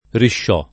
[ rišš 0+ ] (meglio che ricsciò o ricsò )